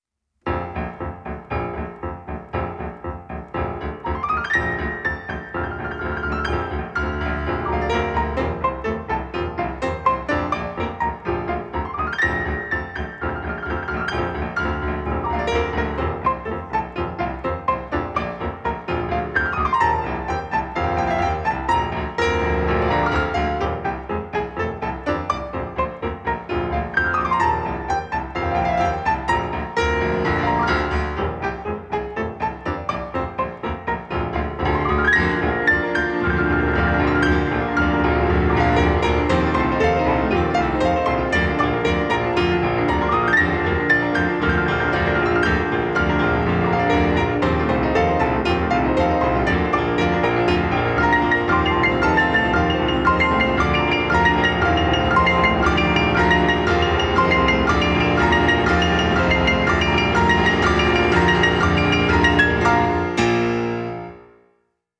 Two pianos
a live recording of a two piano recital
The combined sound of two concert grand pianos requires careful planning.